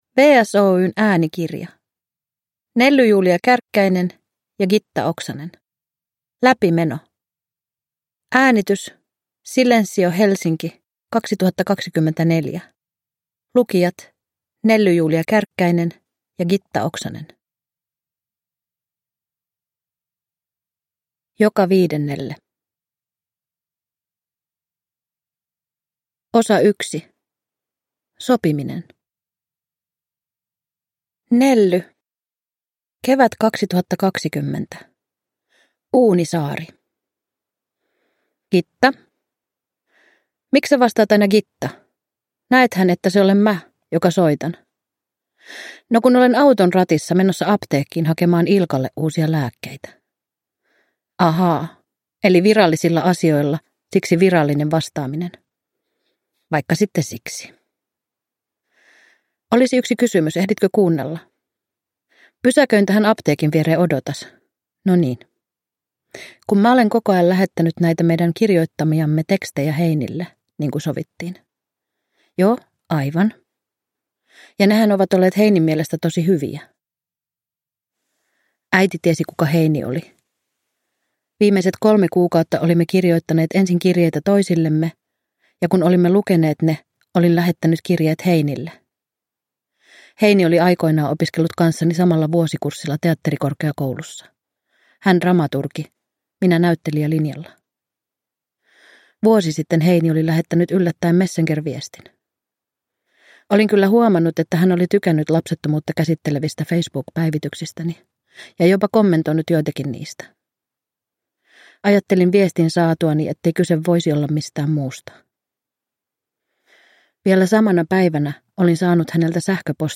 Läpimeno – Ljudbok